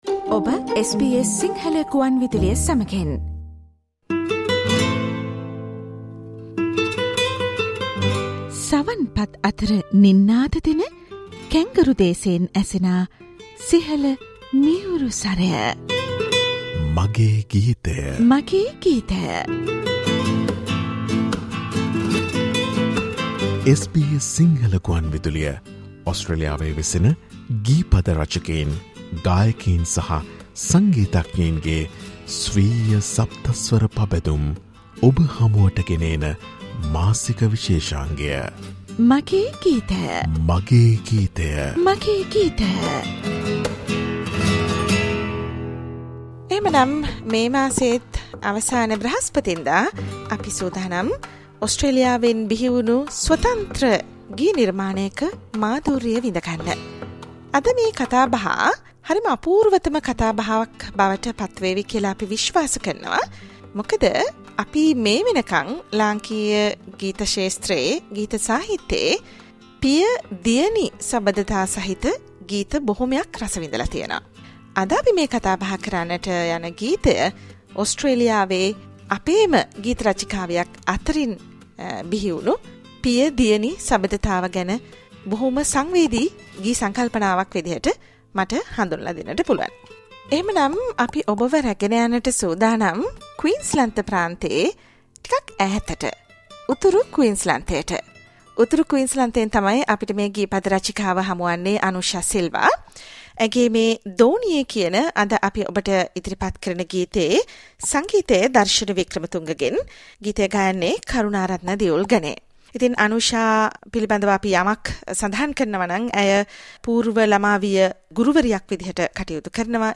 SBS Sinhala 'My Song' musical program.